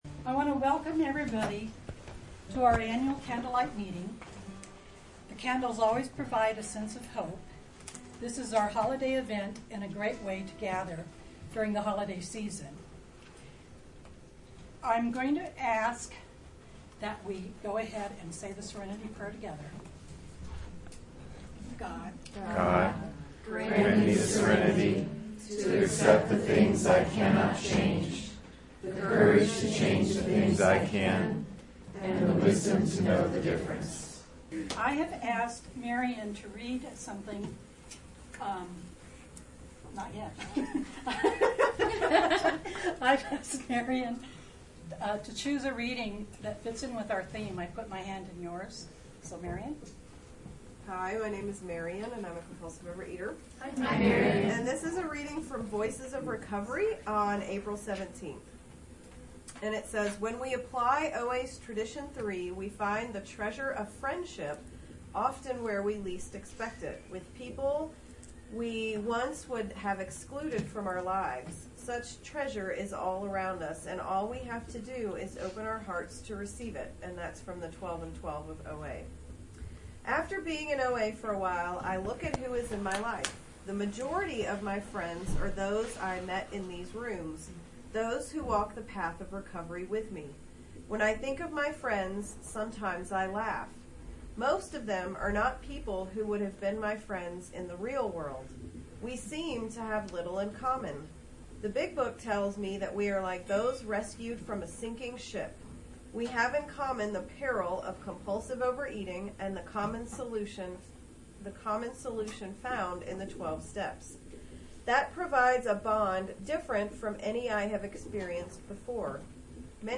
The 2012 Twelve Step Within Day Candlelight Meeting was truly memorable and a powerful gift of recovery.
Candlelight-Meeting-Complete-Recording.mp3